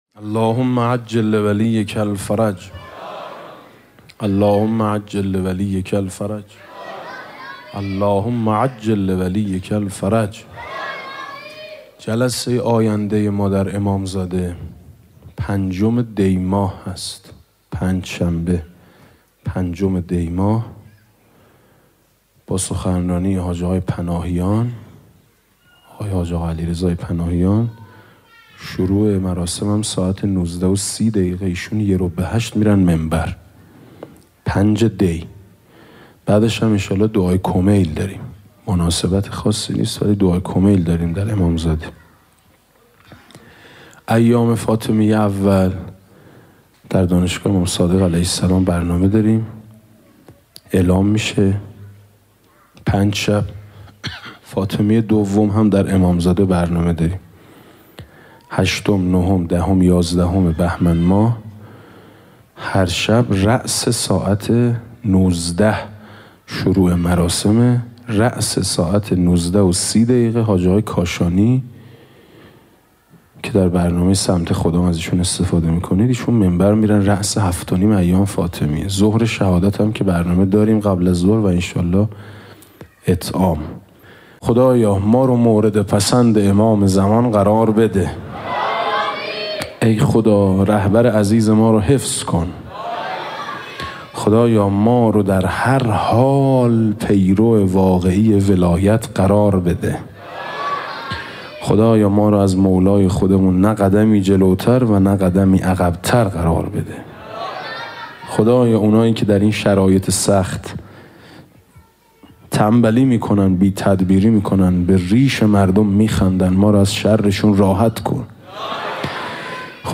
روضه - وظیفه امروز ما جهاد تبیینی است